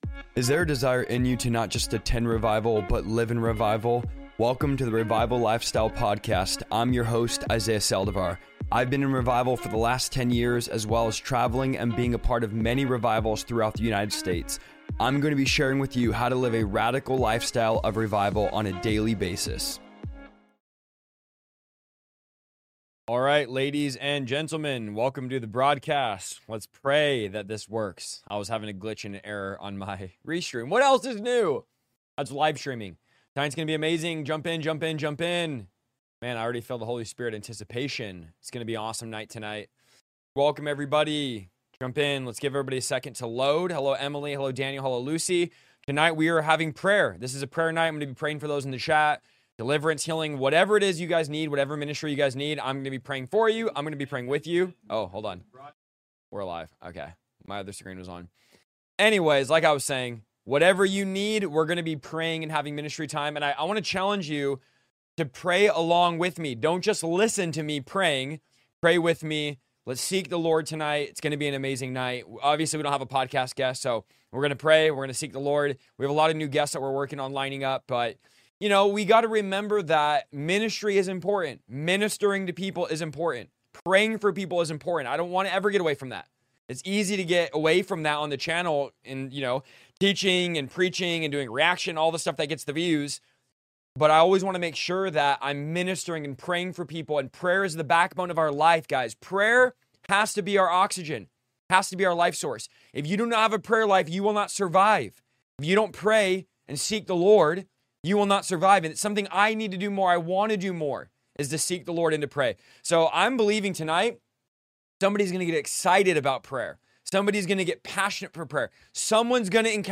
Let Me Pray For You! Ministering To People LIVE